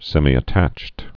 (sĕmē-ə-tăcht, sĕmī-)